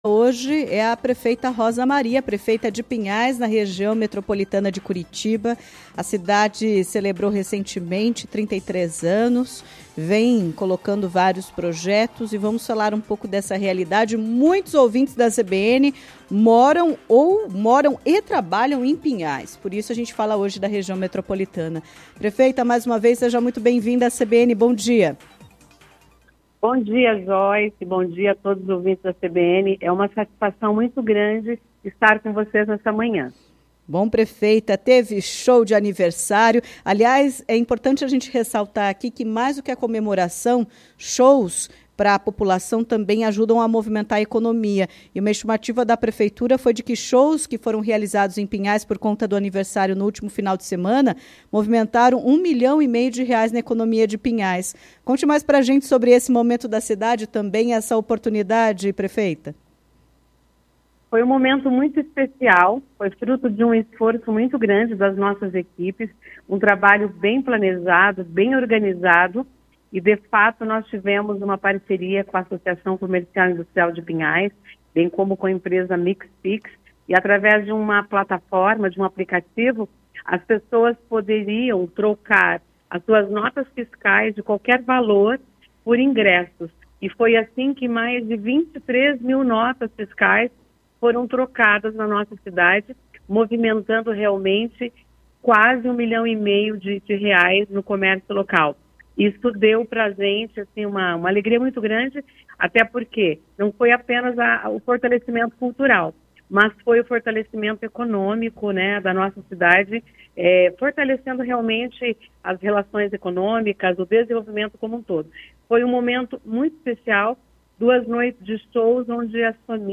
A prefeita de Pinhais, Rosa Maria (PSD), conversou com a jornalista